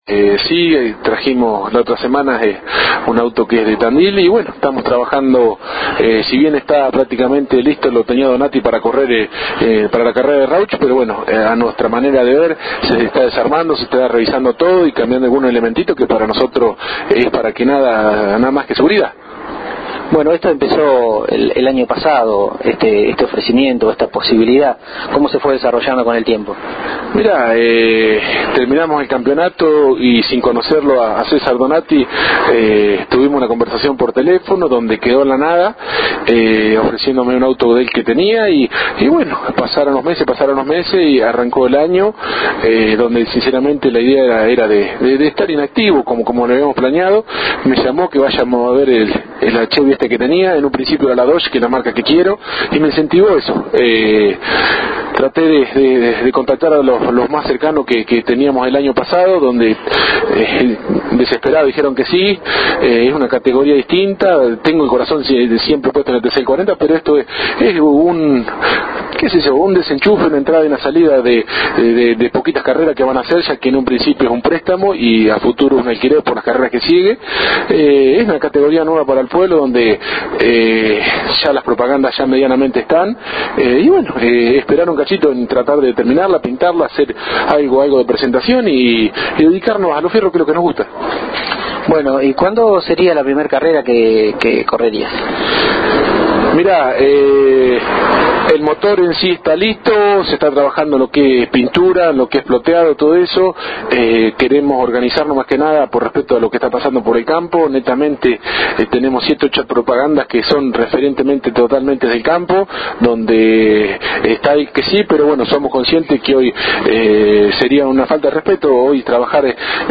En el reportaje que le hicimos nos cuenta la historia de cómo llegó a sus manos este auto y cuales son sus expectativas.